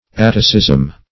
Search Result for " atticism" : The Collaborative International Dictionary of English v.0.48: Atticism \At"ti*cism\, n. [Gr.